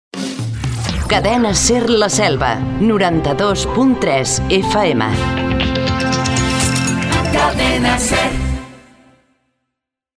Identificació i freqüència de l'emissora
FM